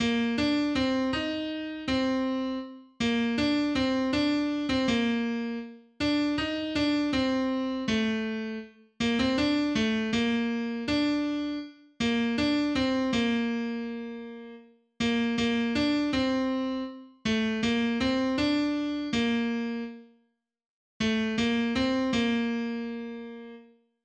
283   "Nur einen Menschen {Bass}" (Bb-Dur, eigene) .pdf .capx .mid